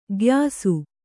♪ gyāsu